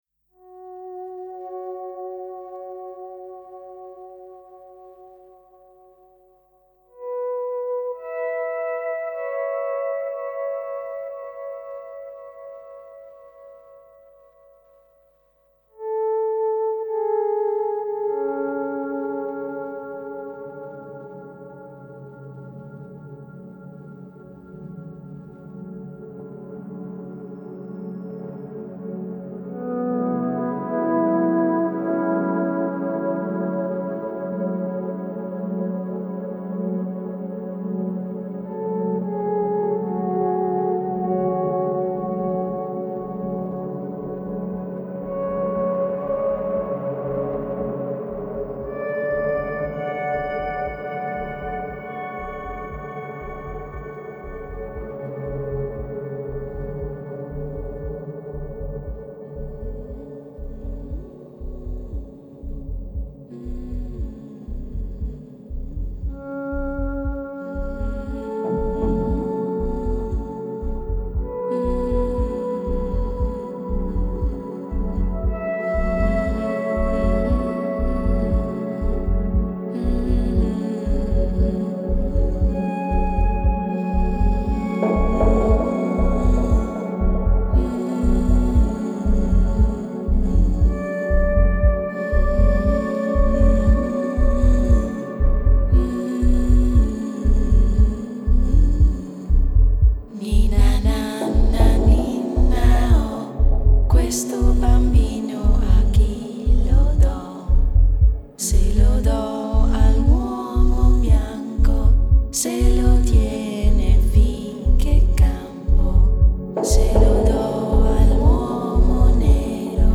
Genre: Chillout, Downtempo, Ambient.